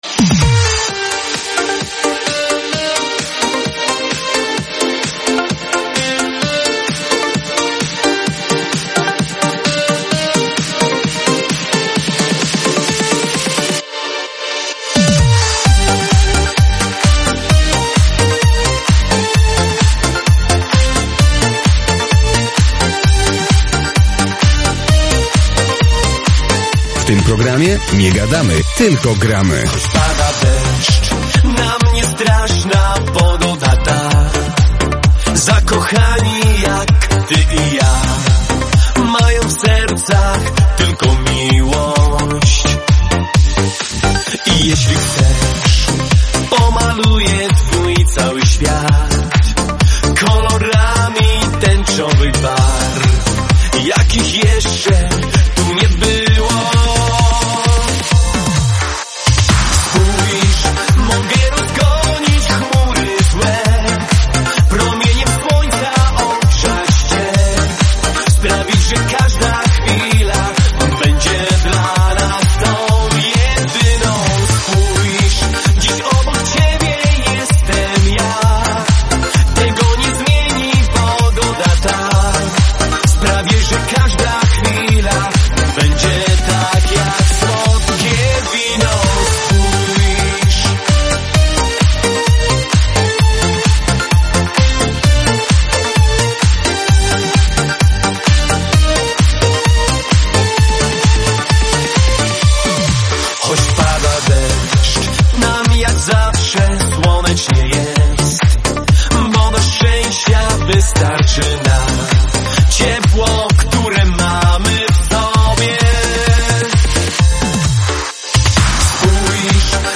- Disco polo